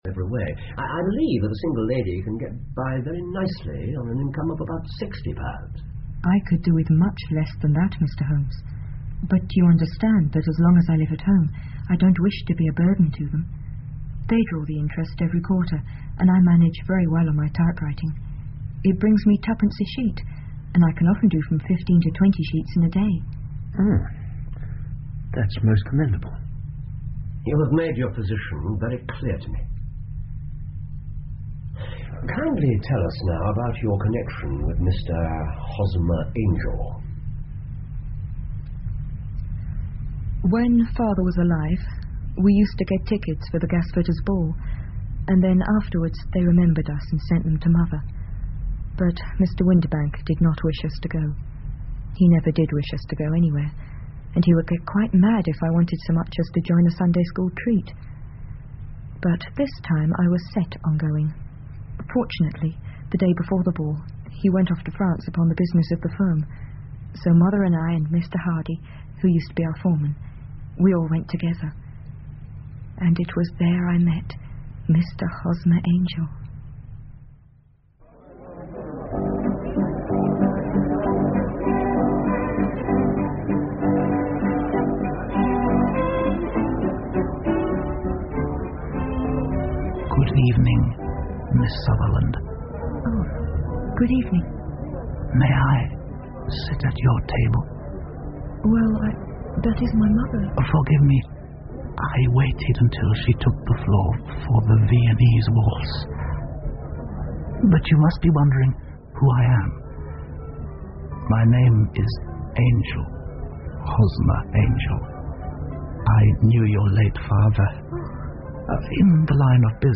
福尔摩斯广播剧 A Case Of Identity 3 听力文件下载—在线英语听力室